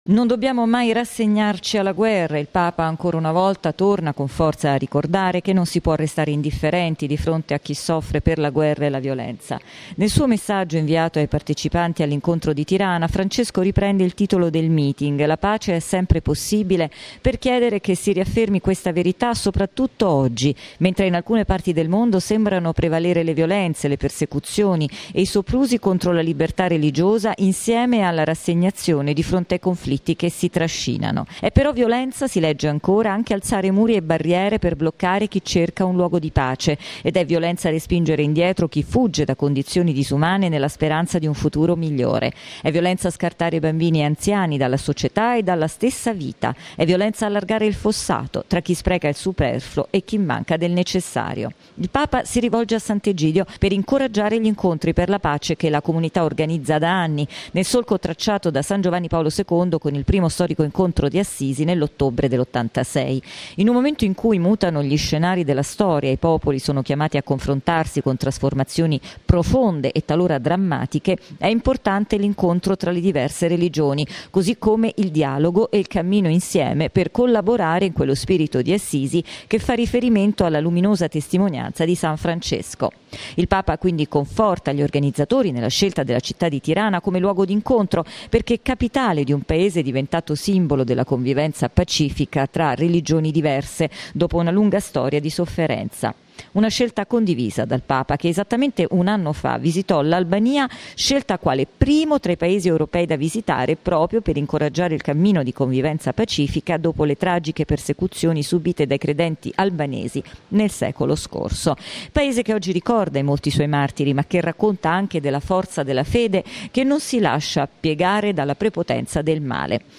Il servizio della nostra inviata